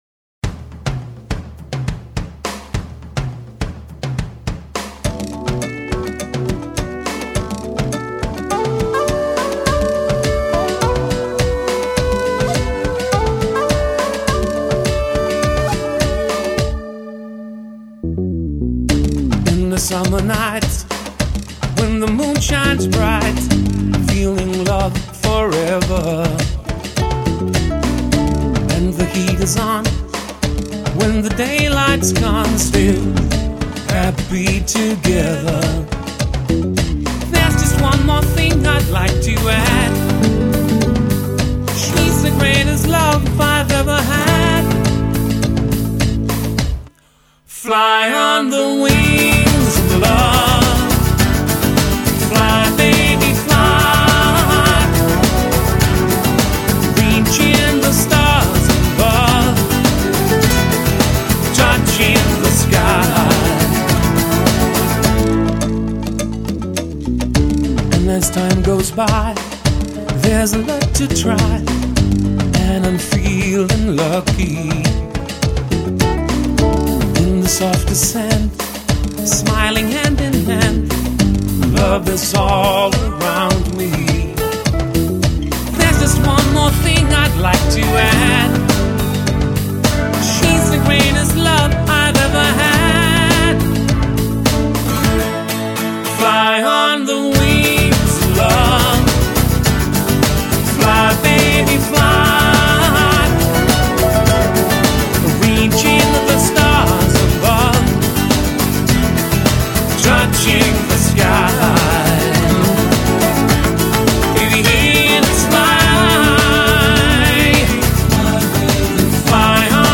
датский поп-рок-дуэт